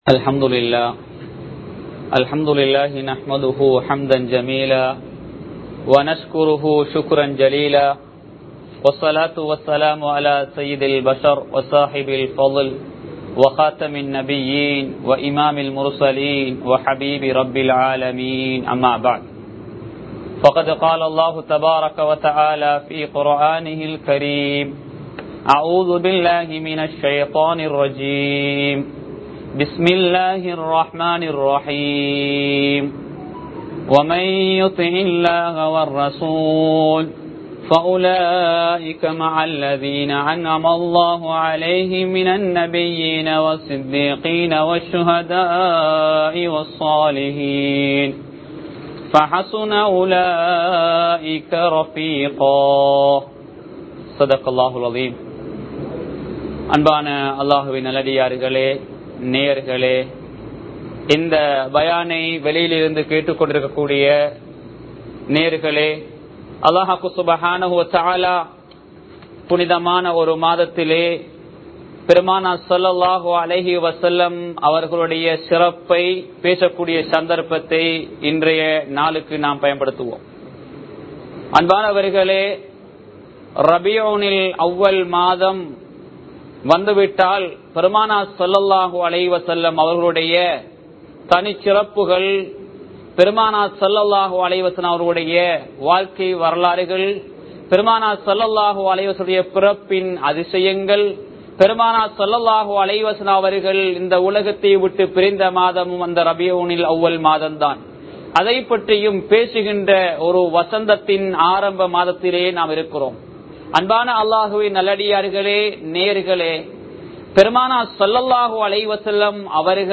நபி(ஸல்) அவர்களினது வருகையின் நோக்கம் | Audio Bayans | All Ceylon Muslim Youth Community | Addalaichenai
Colombo 03, Kollupitty Jumua Masjith